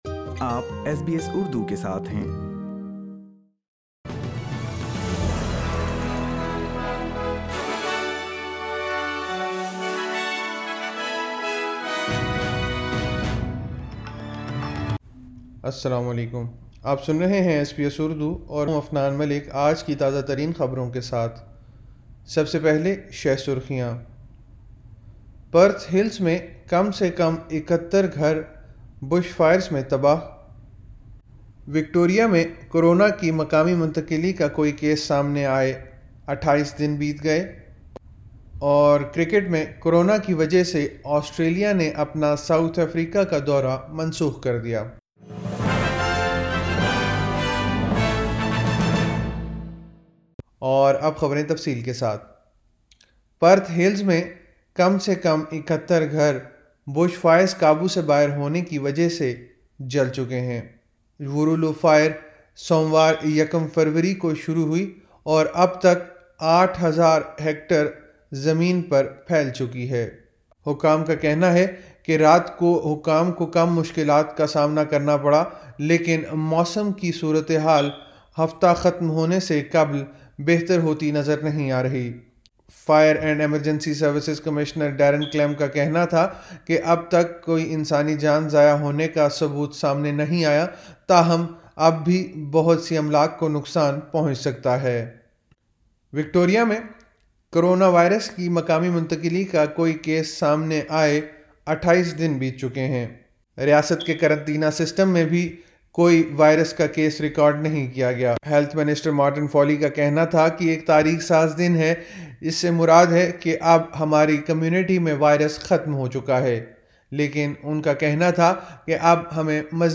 ایس بی ایس اردو خبریں 03 فروری 2021